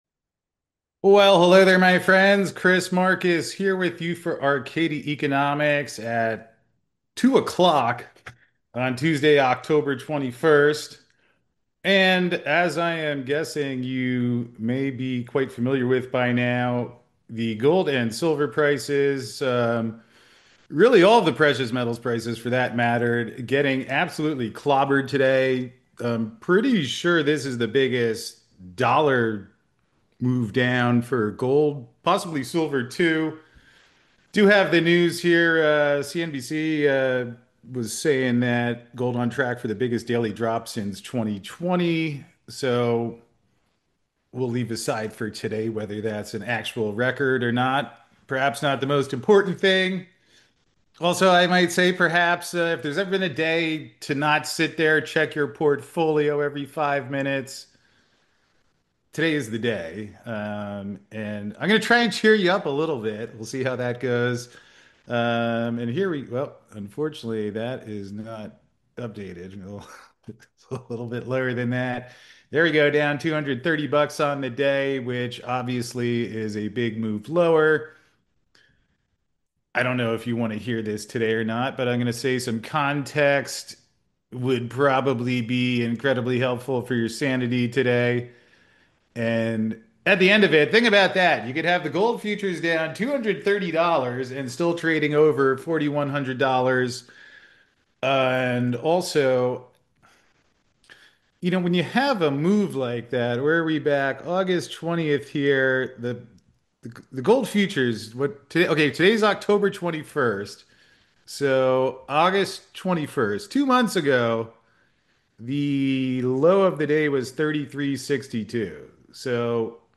So to find out more, join us for this live call!